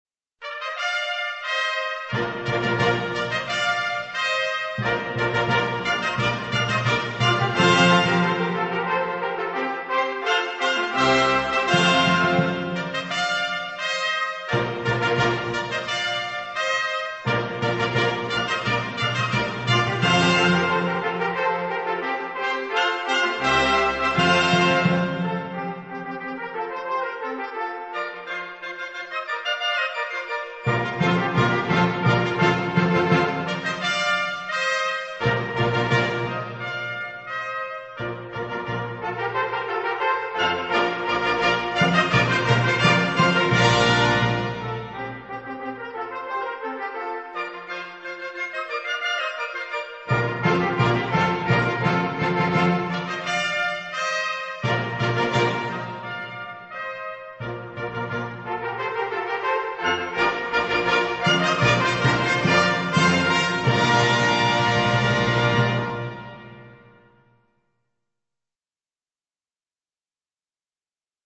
Gattung: Intraden
Besetzung: Blasorchester